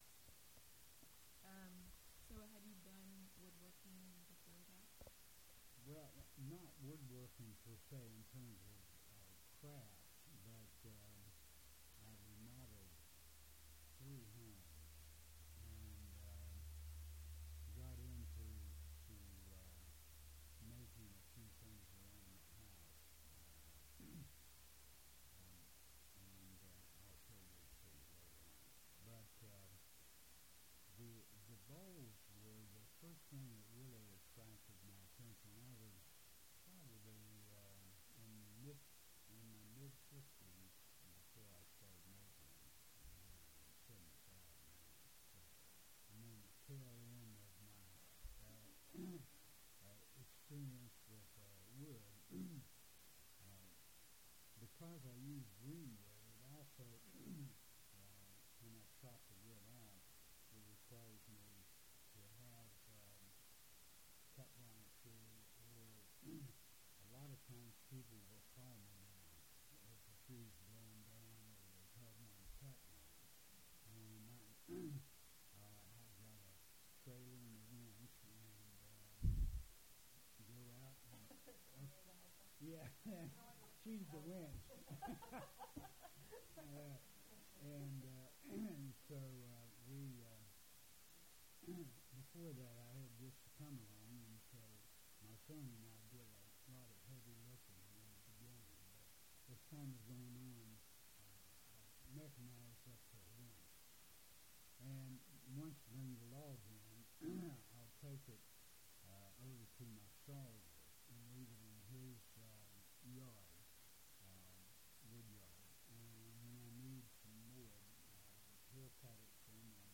This is part 2 of 5 files constituting the full interview.